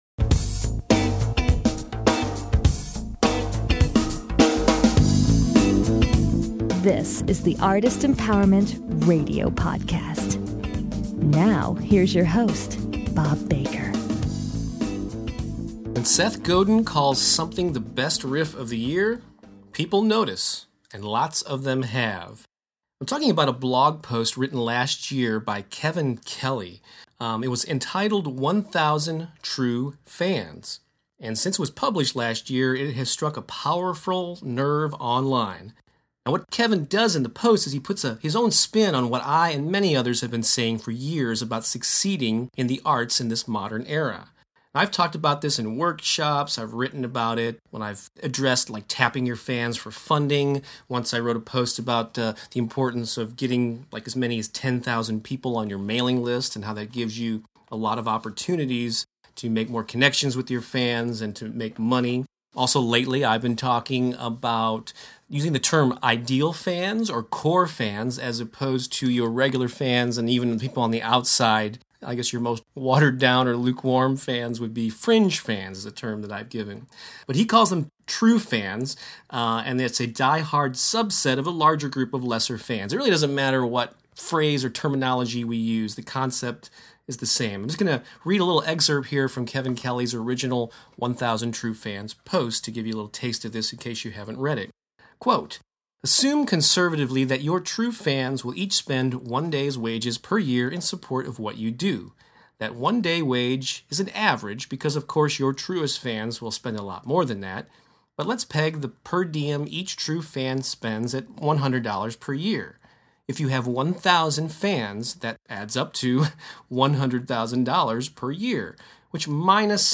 The show intro music is the beginning groove